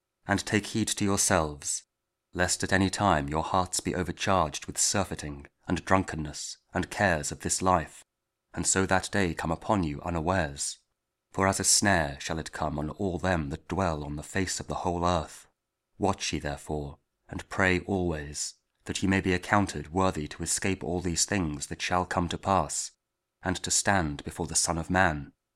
Luke 21: 34-36 – Week 34 Ordinary Time, Saturday; Sunday Advent 1 (Audio Bible KJV, Spoken Word)